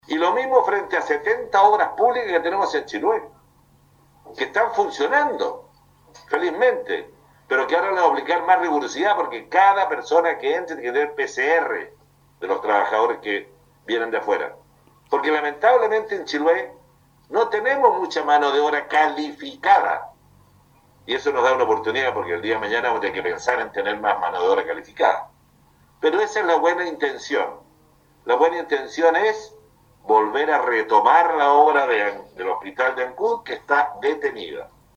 Sobre la medida que instruyó el ministro de Salud Enrique Paris, de mantener la actual suspensión de los trabajos de construcción del nuevo Hospital de Ancud, se refirió el intendente de Los Lagos.